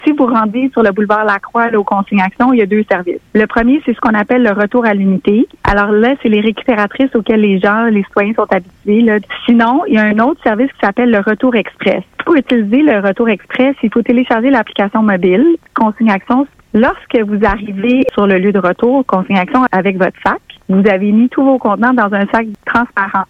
En entrevue à Radio-Beauce